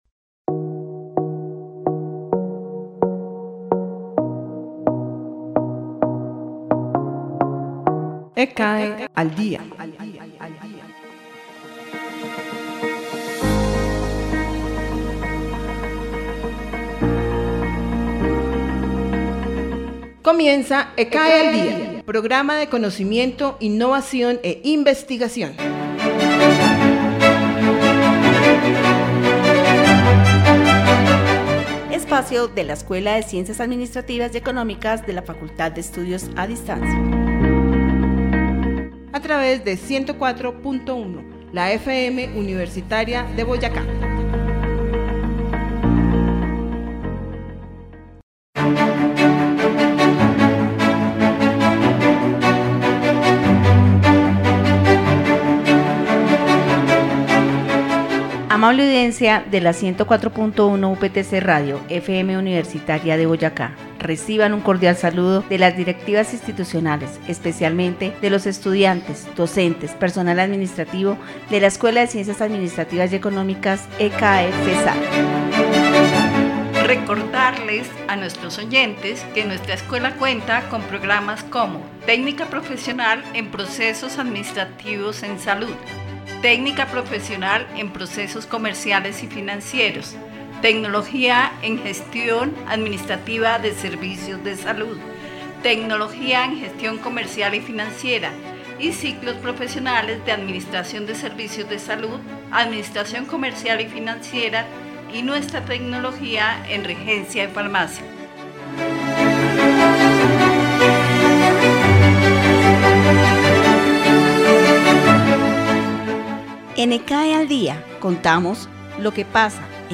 programa que se trasmite a través de la emisora 104.1 FM Universitariade Boyacá